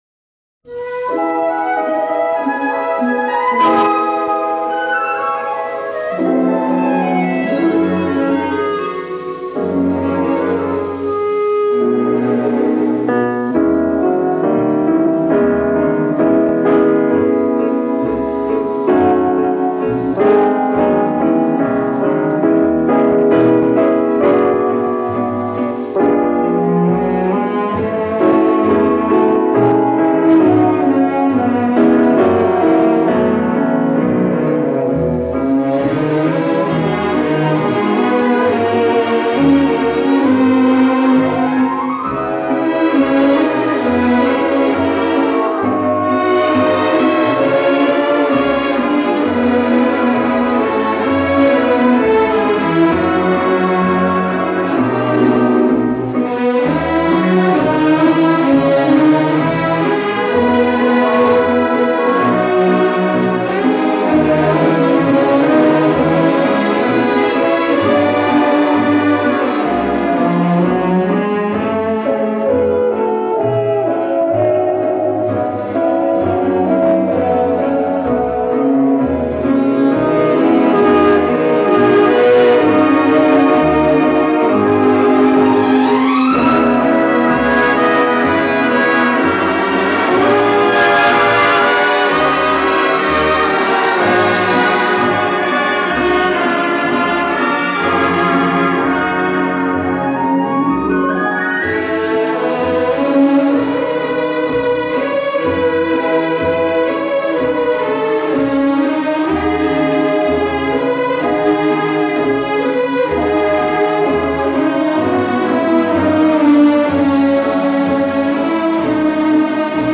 медленный фокстрот